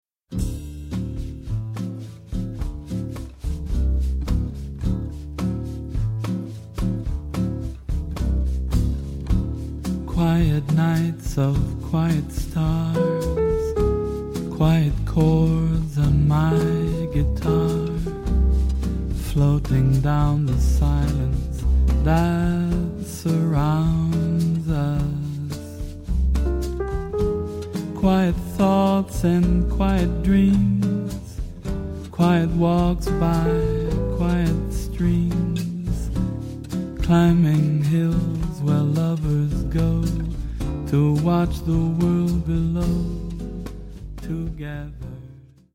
飾らないサウンドとなでるような歌声に気持ちをほだされる、粋で洒落たボッサ＆ジャジーな夜に、あなたは何を想う…？